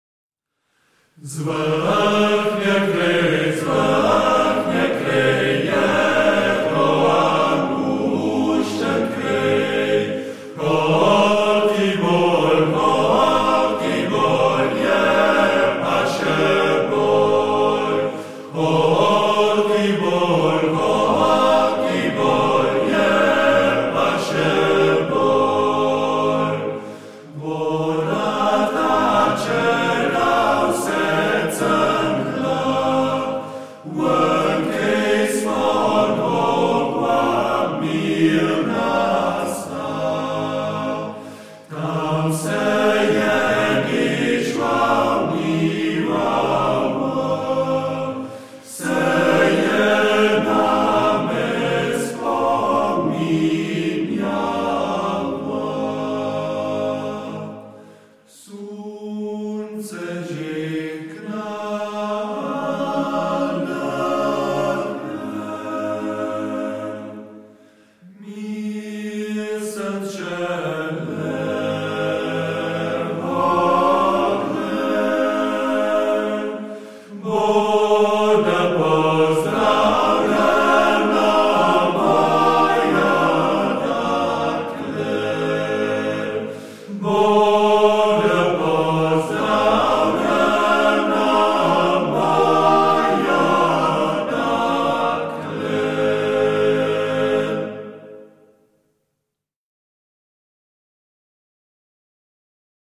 koroška narodna